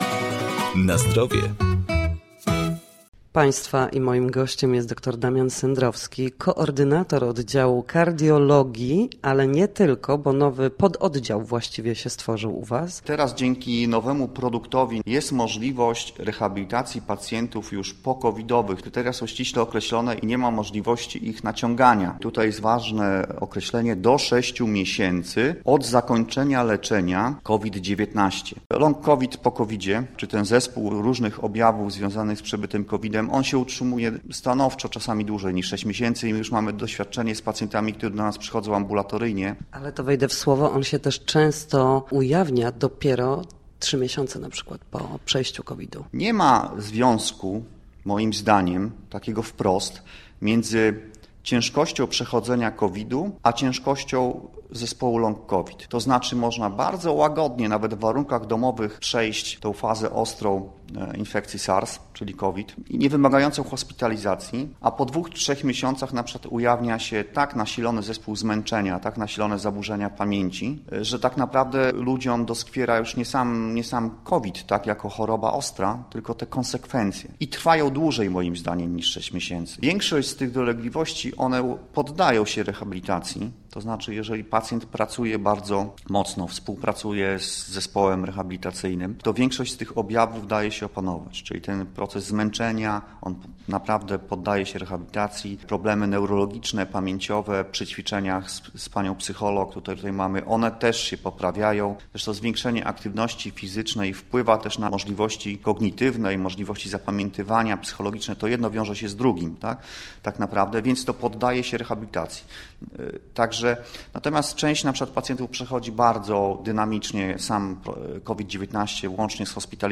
Nasi goście, lekarze, fizjoterapeuci, w audycji „Na Zdrowie” będą odpowiadać na najistotniejsze pytania.